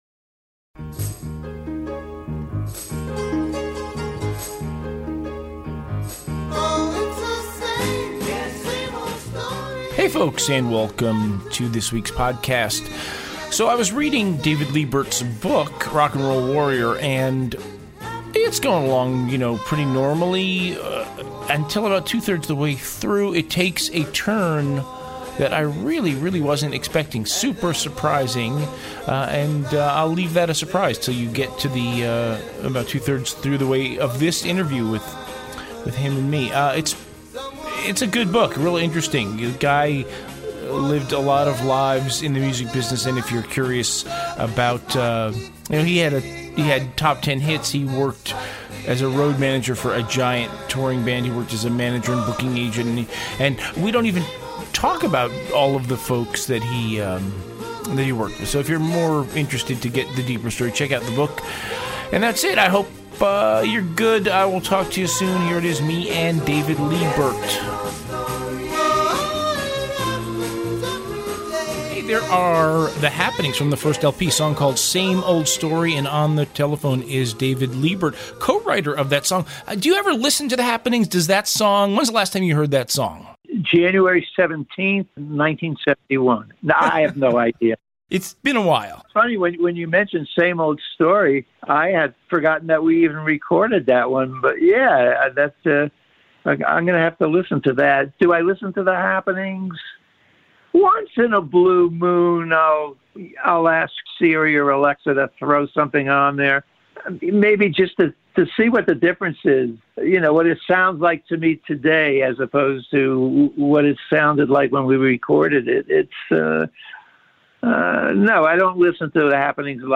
"Interview"https